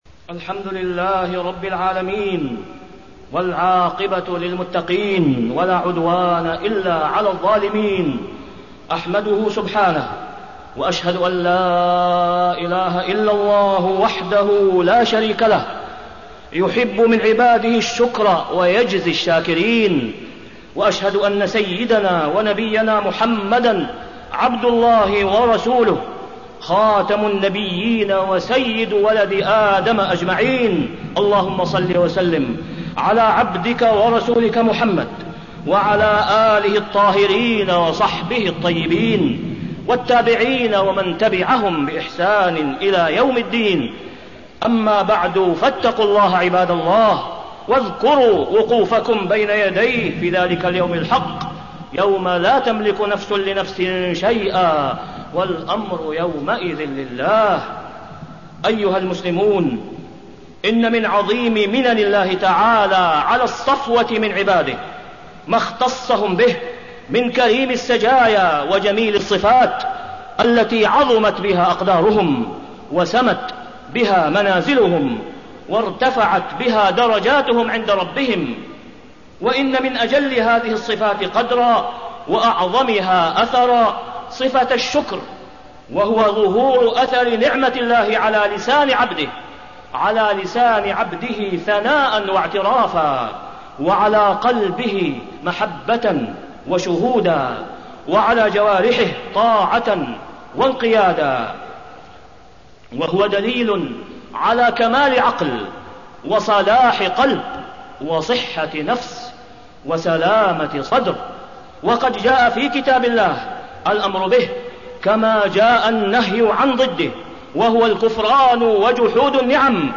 تاريخ النشر ٤ صفر ١٤٣٠ هـ المكان: المسجد الحرام الشيخ: فضيلة الشيخ د. أسامة بن عبدالله خياط فضيلة الشيخ د. أسامة بن عبدالله خياط الشكر وأثره في حياة المسلم